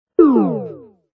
error-tip.mp3